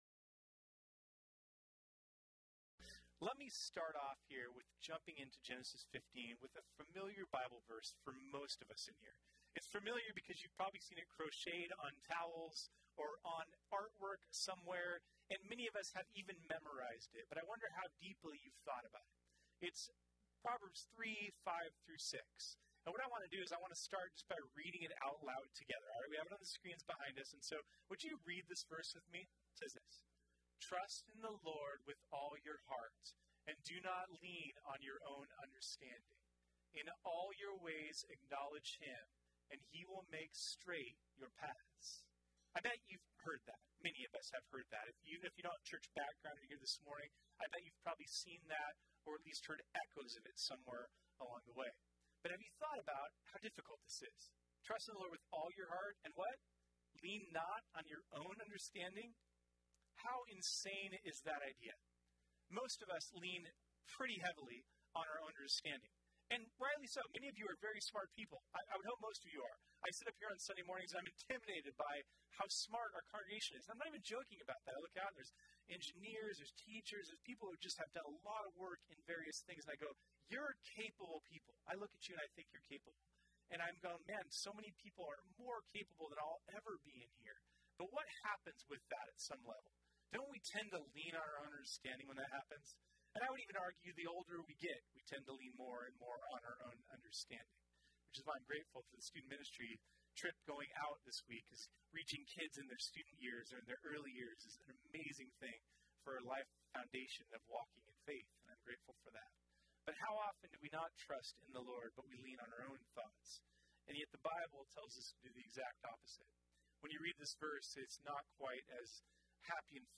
This sermon was originally preached on Sunday, February 24, 2019.